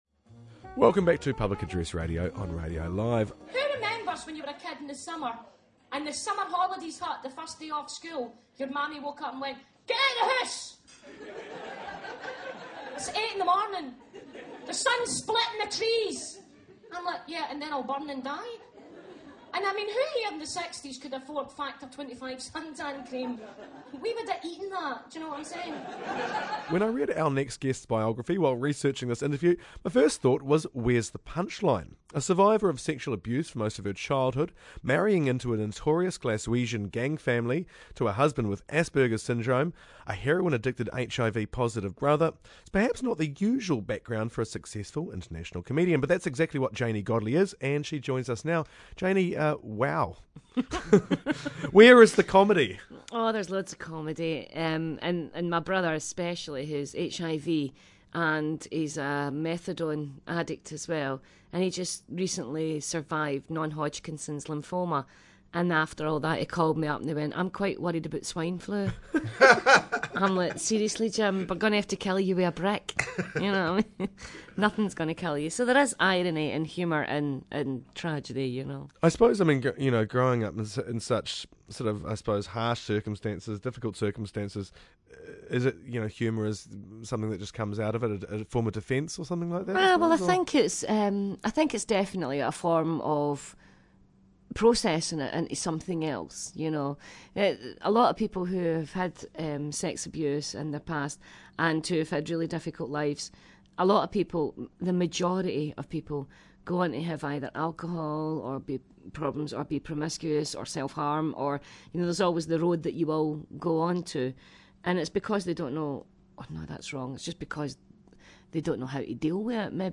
talk to Glaswegian comedian Janey Goldey, in town for the NZ International Comedy Festival.